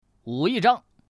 Index of /qixiGame/release/guanDan/jsGuangDian/assets/res/zhuandan/sound/woman/